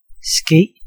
Ääntäminen
US : IPA : /ˈhæp.ən/ UK : IPA : /ˈhapən/